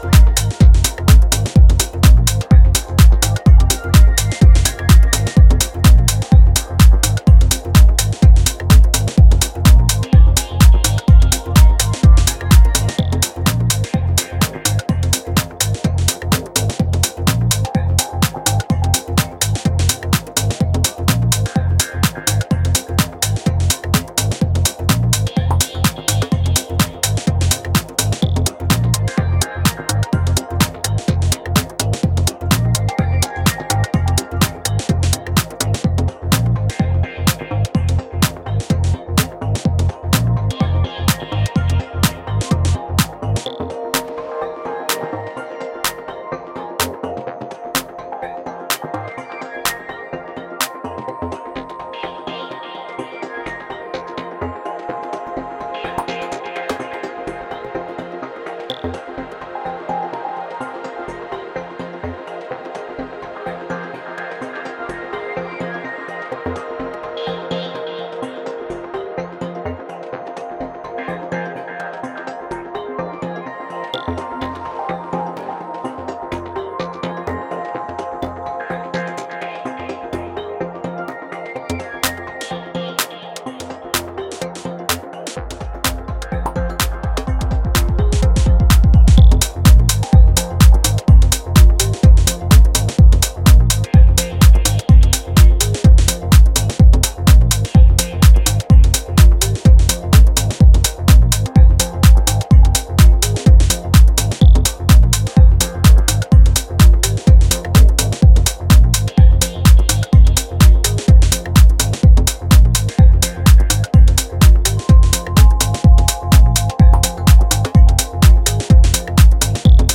dancefloor banger remix